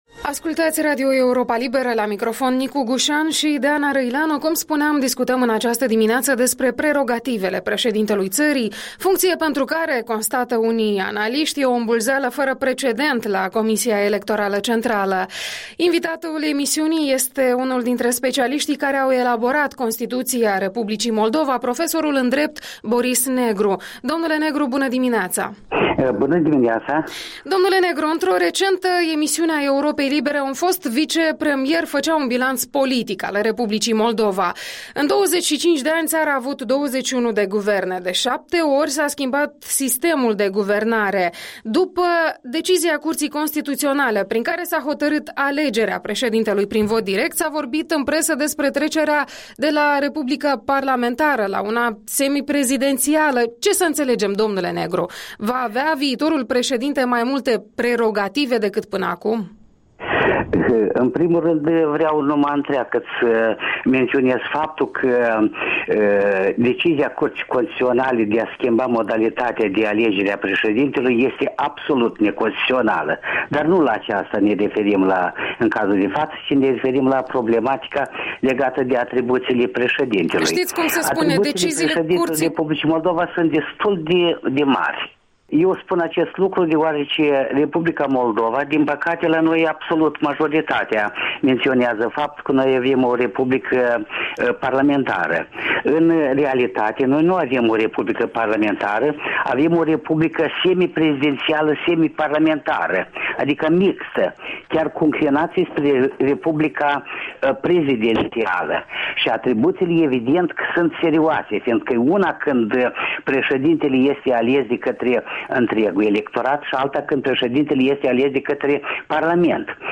Interviul dimineții cu un profesor de drept care a participat la elaborarea Constituției R.Moldova.